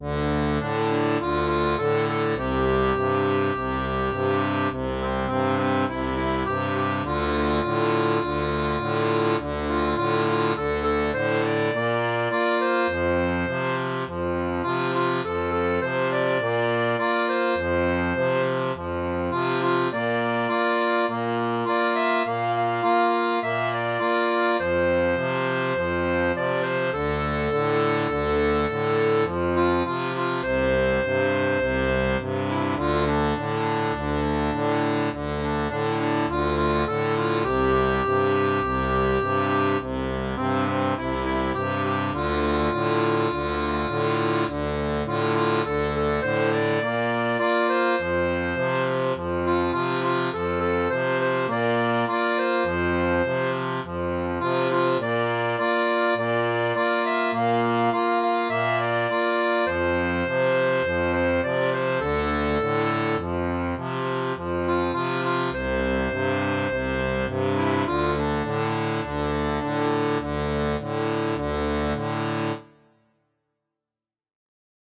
• Une tablature en C pour diato à 3 rangs
Pop-Rock